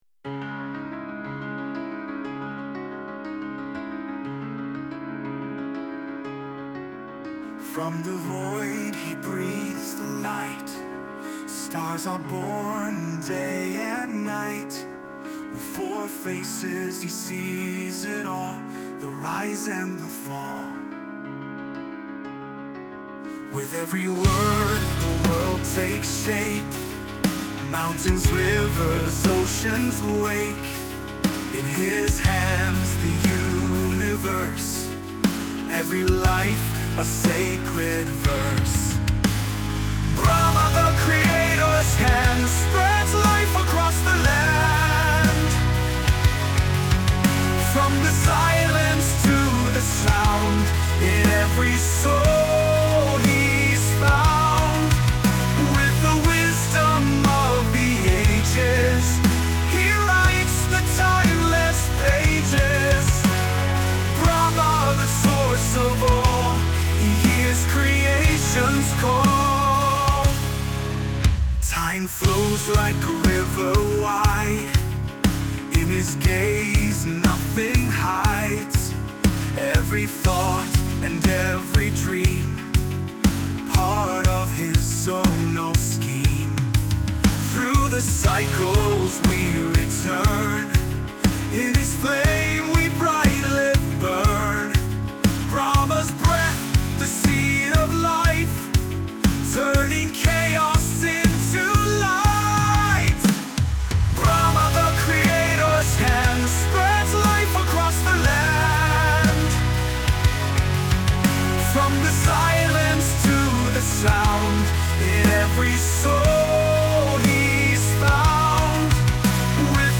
EstiloMetal Melódico